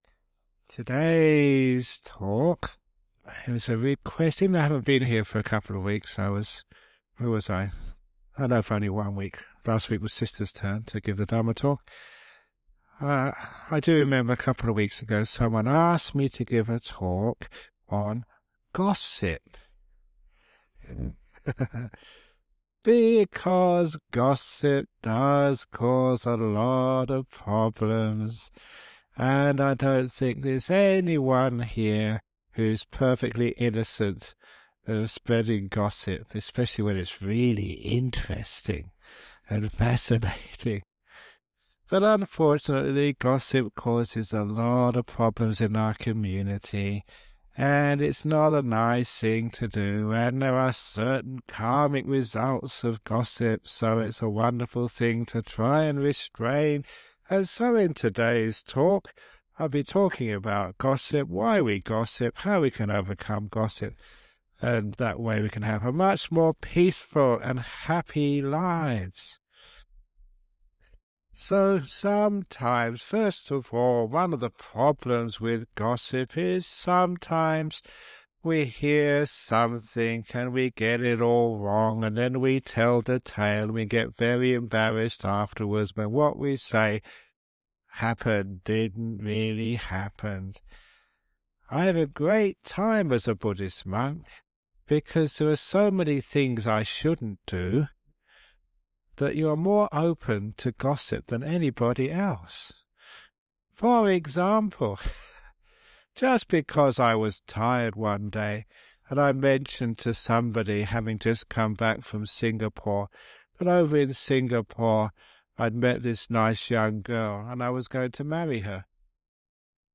This talk discusses the negative effects of gossip and negative speech in society. Ajahn Brahm argues that gossip and negative speech are products of our competitive and critical society, where people are constantly putting others down in order to elevate themselves.
It has now been remastered and published by the Everyday Dhamma Network , and will be of interest to his many fans.